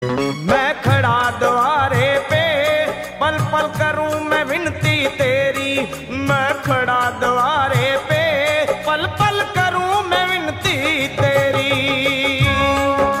Bhakti Ringtones Devotional Ringtones